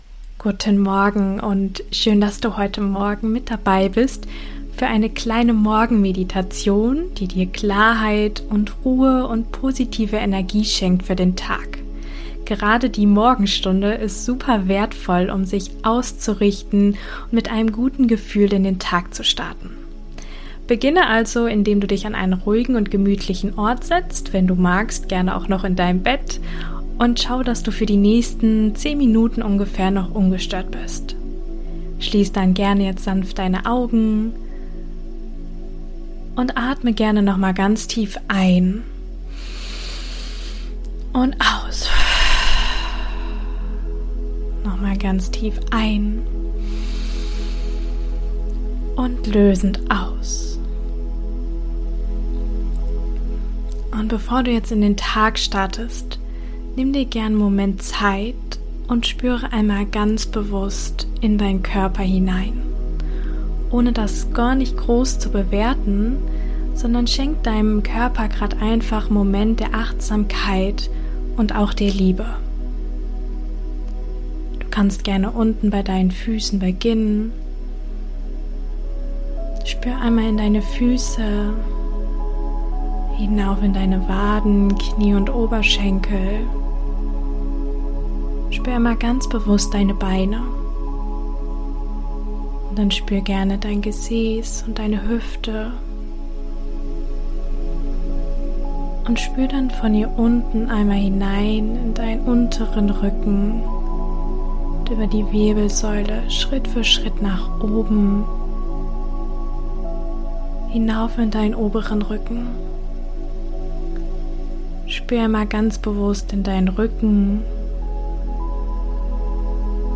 25 - Morgenmeditation ~ Mindflow - Der Podcast für mentale Gesundheit Podcast